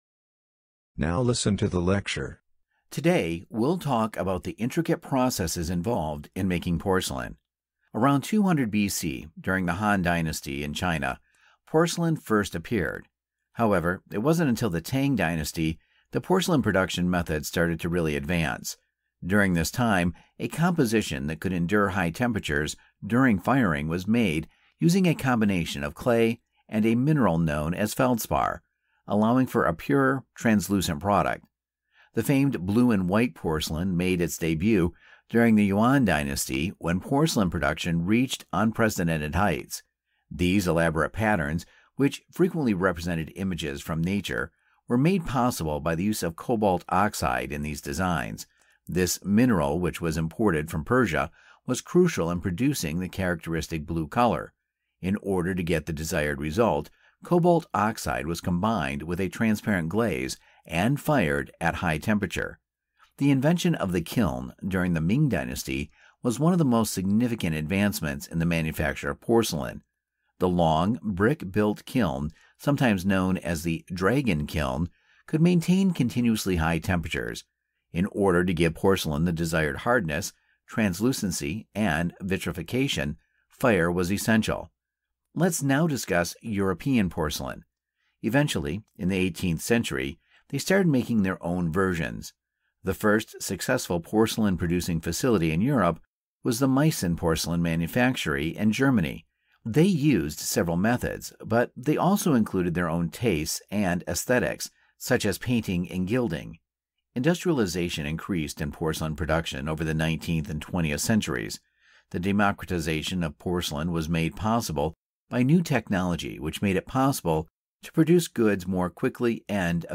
Directions: This section measures your ability to understand conversations and lectures in English.